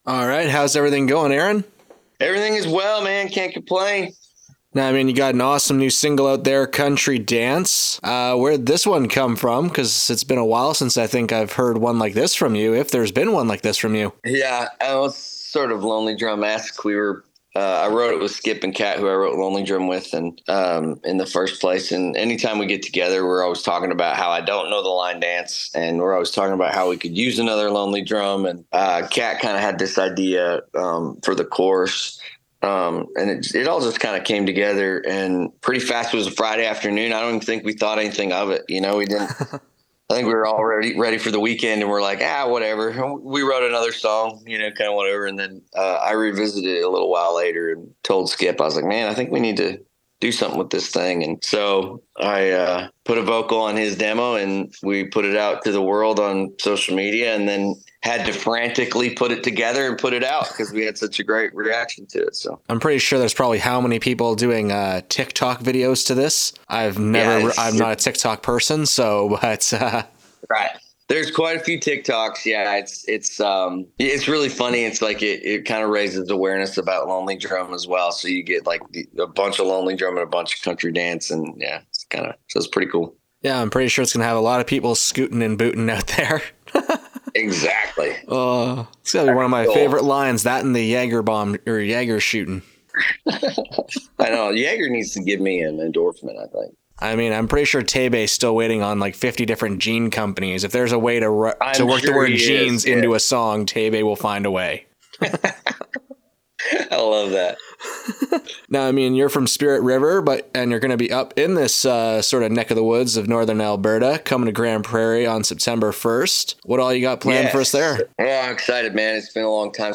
Aaron Goodvin Interview